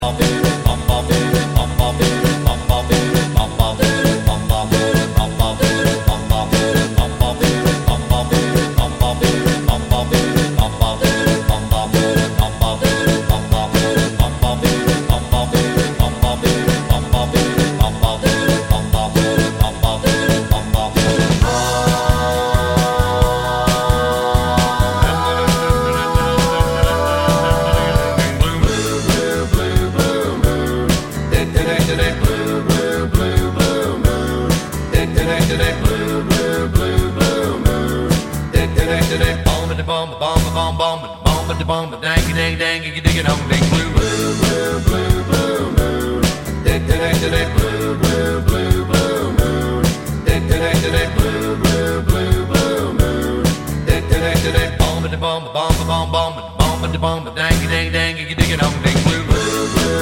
no Backing Vocals Rock 'n' Roll 2:16 Buy £1.50